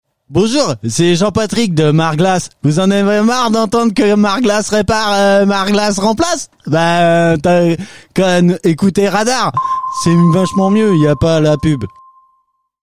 Retrouvez ici les parodies publicitaires qui agrémentent l'agenda culturel "Qu'est ce qu'on fait quand ?"